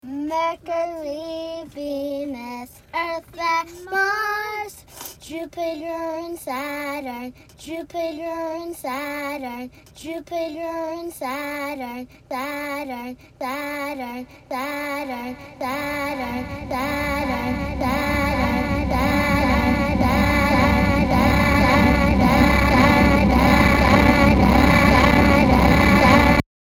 Hip Hop/Rap